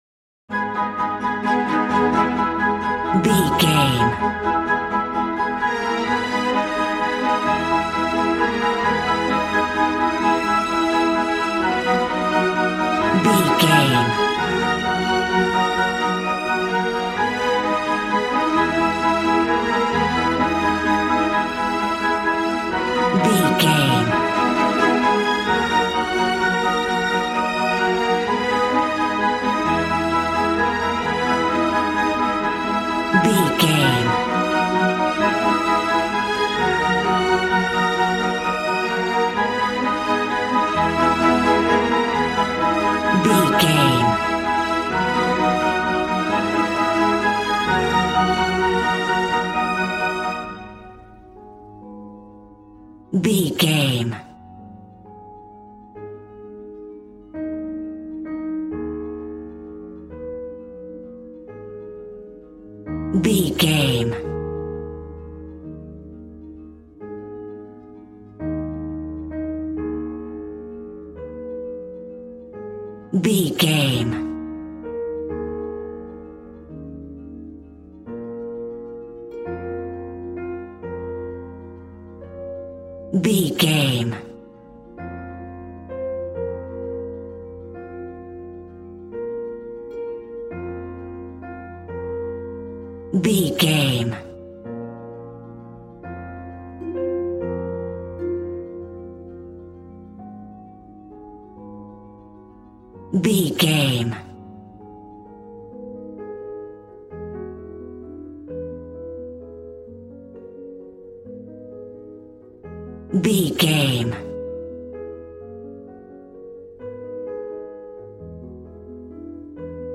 Regal and romantic, a classy piece of classical music.
Ionian/Major
G♭
regal
strings
violin